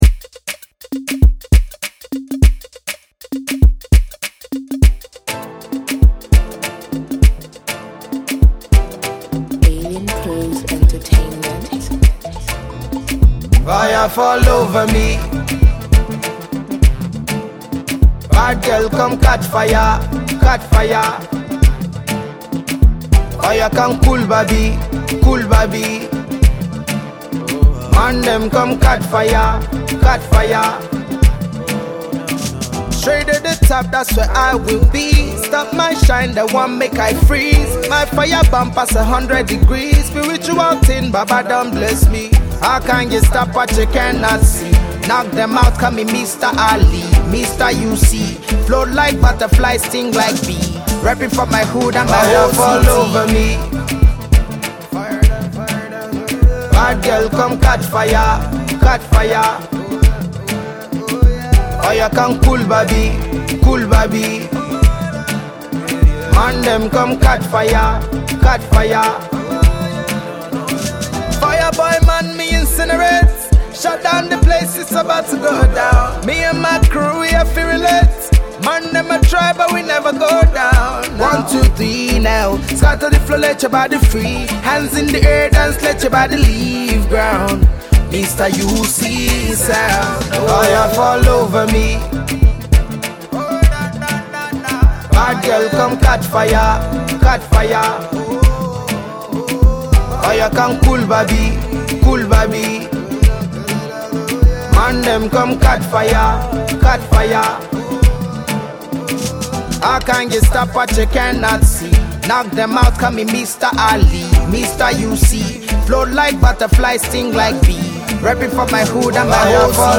Afro-Pop artiste